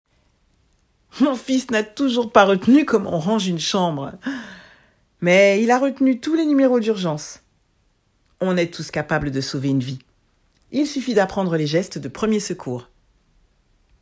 25 - 60 ans - Soprano